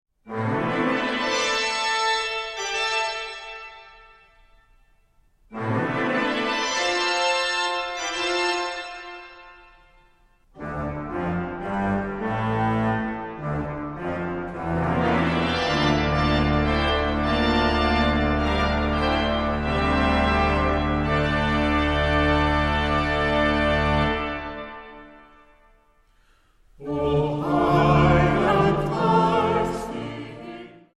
Festliche Advents- und Weihnachtsmusik
Vocal- und Orgelmusik Neuerscheinung 2010